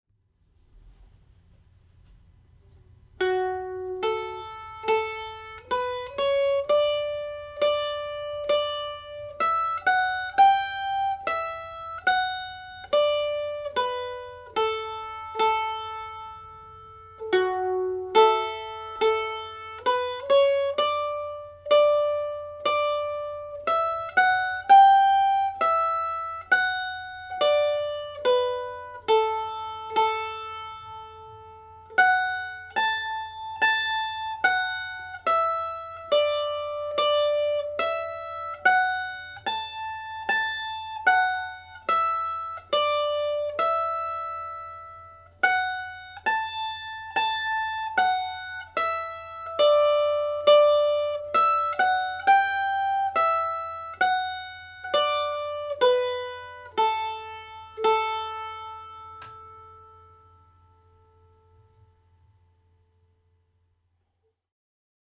Polka (D Major)
Spanish Lady played slowly